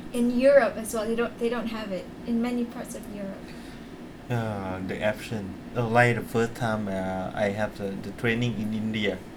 S1 = Brunei female S2 = Laos male Context: S1 was talking about a trip she had taken to Paris, and getting a waiter there to understand that she wanted some chilli sauce.
Discussion: There is no clear [k] in accent .